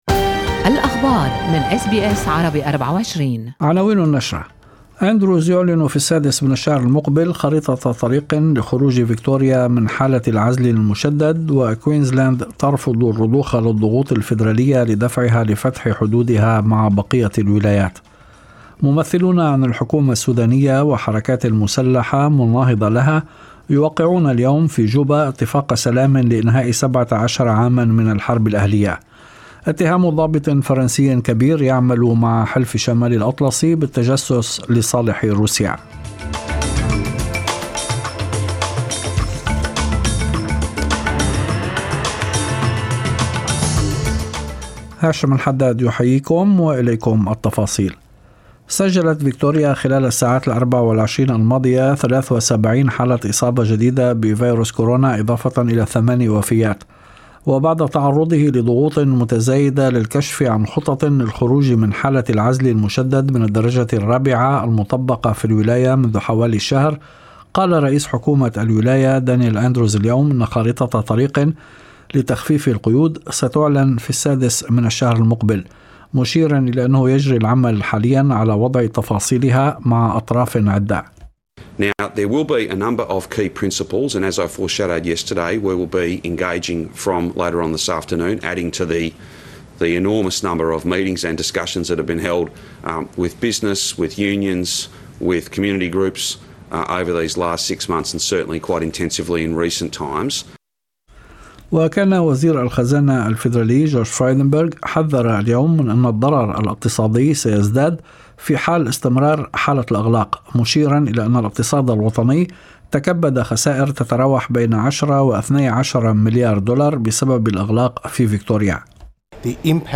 نشرة أخبار المساء 31/8/2020